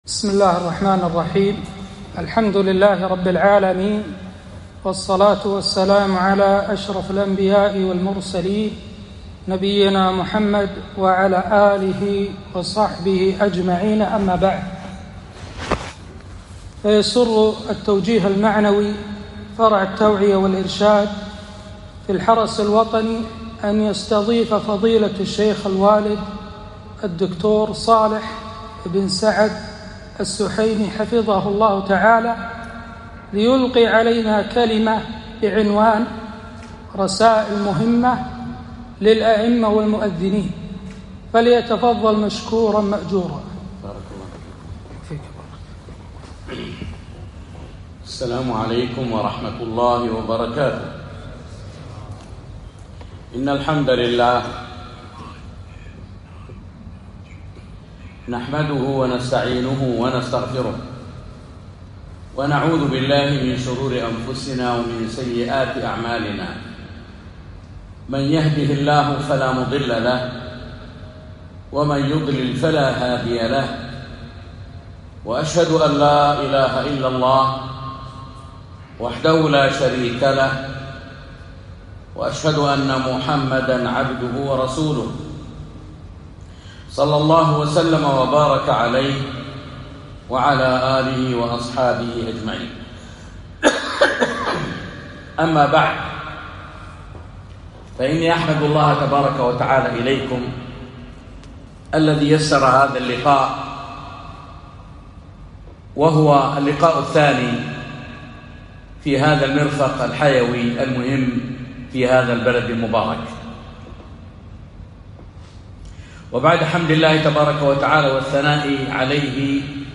محاضرة - رسائل مهمة للأئمة والمؤذنين